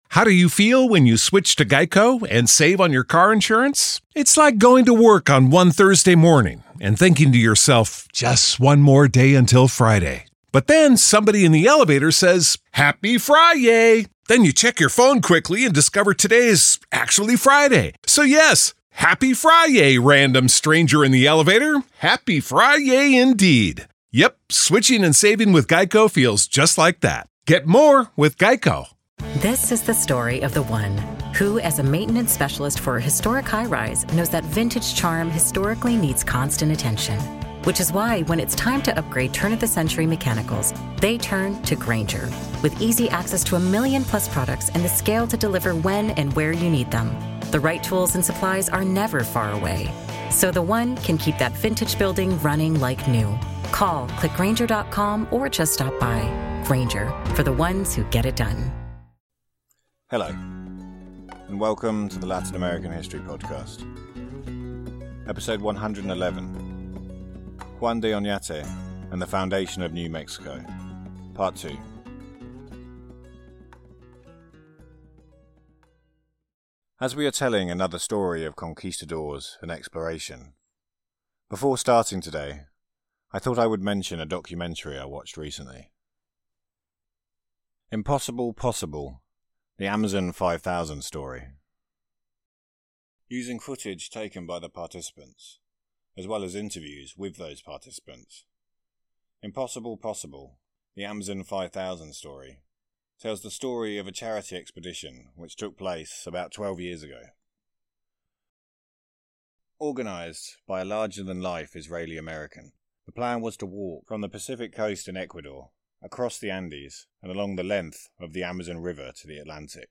1 La Flor de Cempasúchil - Historia, Cultura y Leyendas - The Cempasúchil Flower - History, Culture and Legends 25:24 Play Pause 9d ago 25:24 Play Pause Play later Play later Lists Like Liked 25:24 In this lively discussion, we share our experience celebrating Día de Muertos in Cholula, Puebla, surrounded by the beautiful cempasúchil fields and festive atmosphere. We dive into the origins and uses of the marigold in Mexico, from its practical applications in gardening and health to its presence in cuisine and legend.